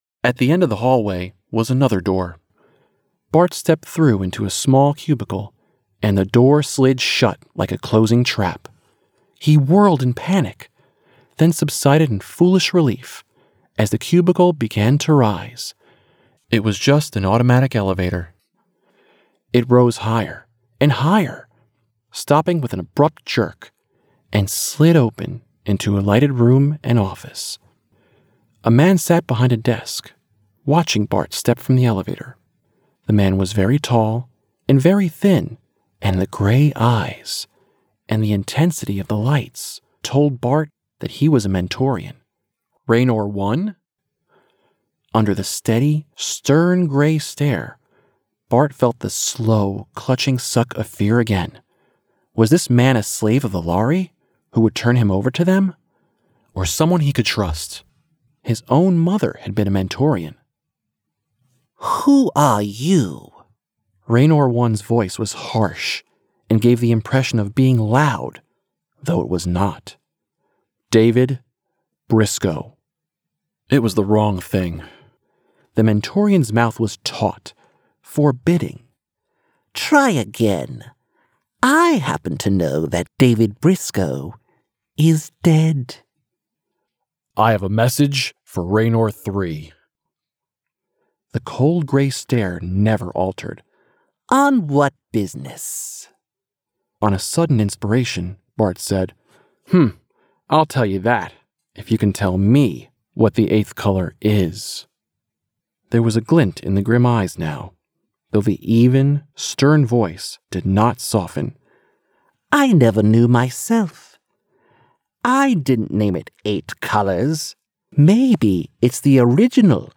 at Antland Studios, Bloomfield, NJ
Audiobook Demo